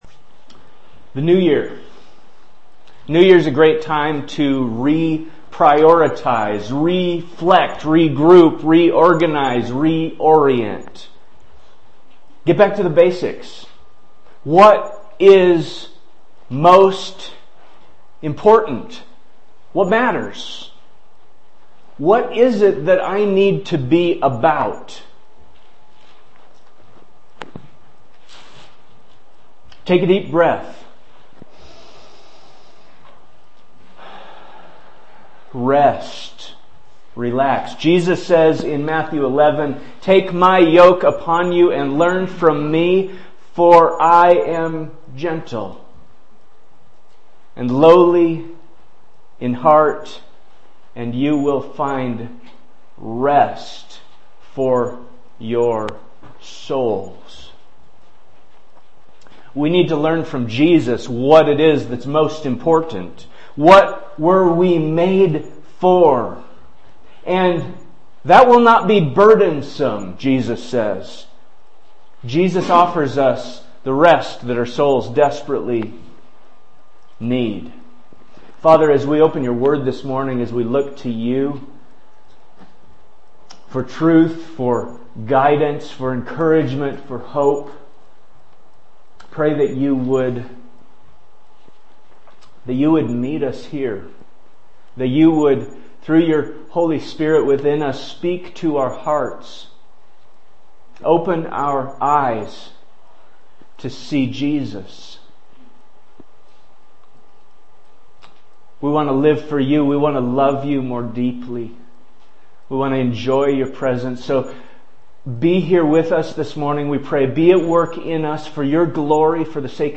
Sermon Manuscript